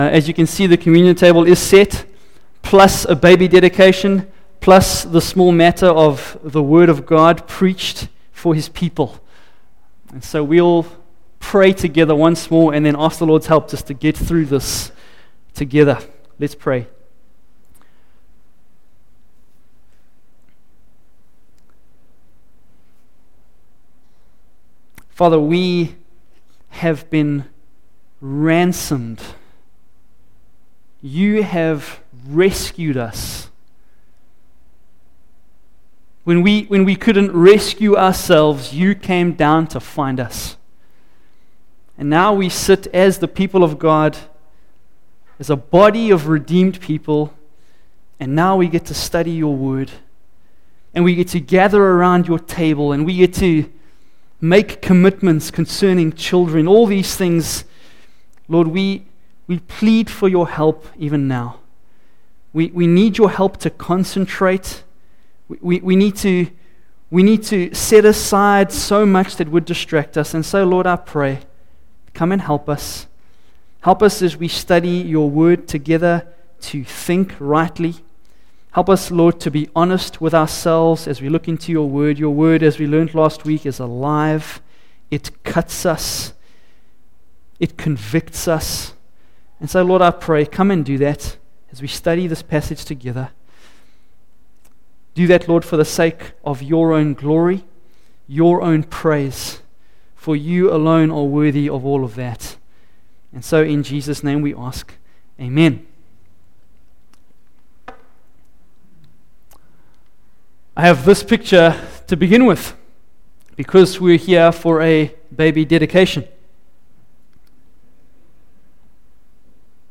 Facebook Twitter email Posted in Evening Service